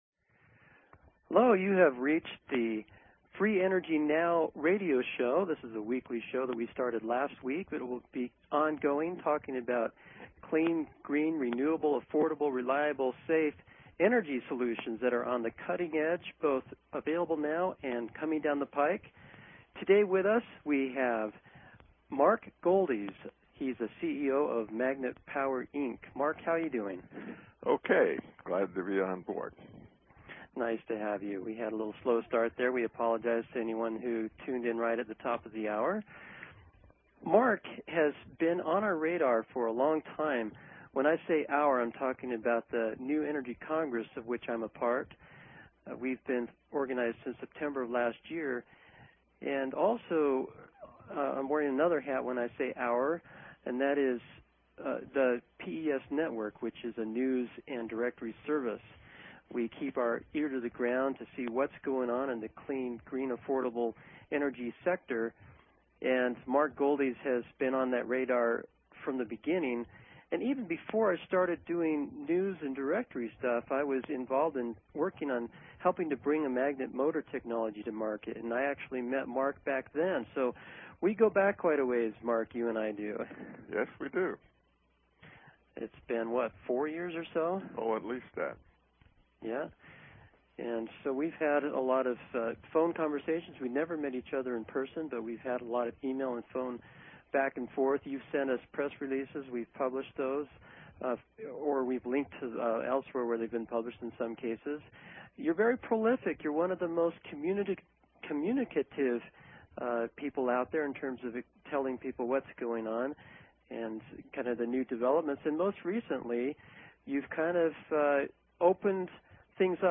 Talk Show Episode, Audio Podcast, Free_Energy_Now and Courtesy of BBS Radio on , show guests , about , categorized as